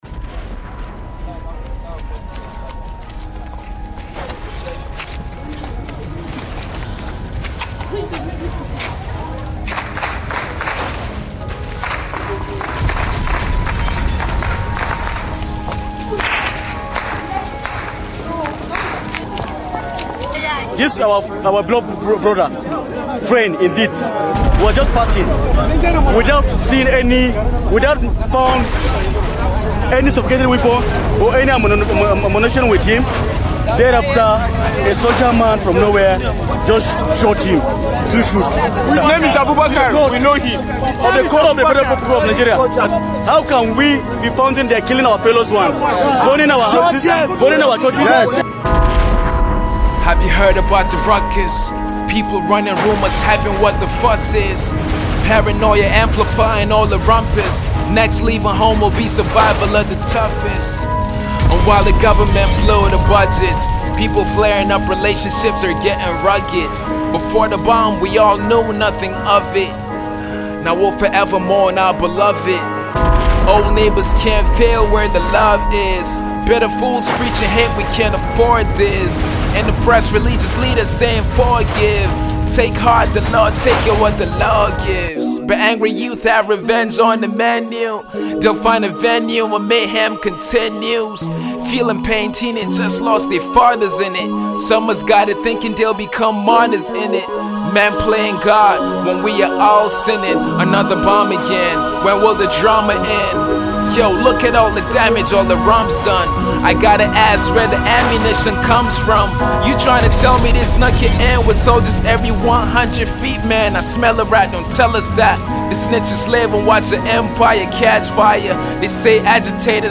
all the while staying true to its core hip-hop roots.